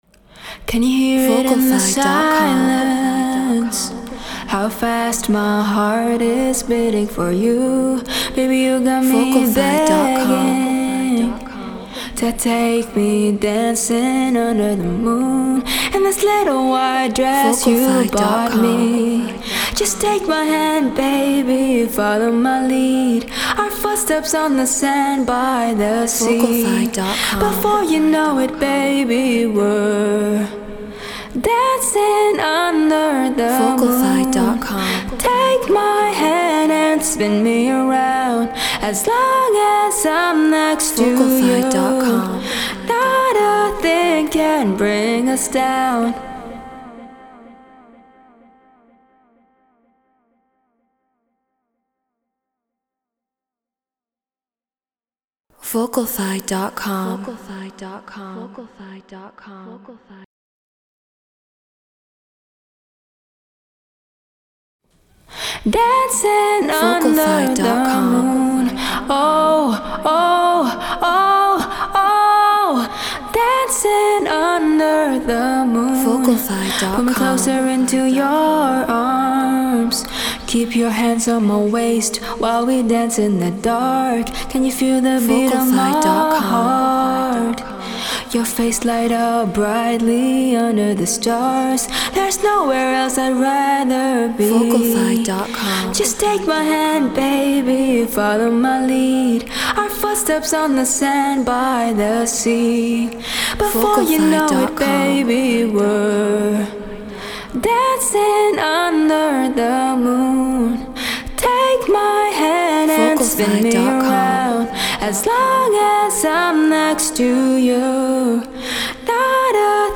Get Royalty Free Vocals.
High Quality WAV. Non-Exclusive Vocal.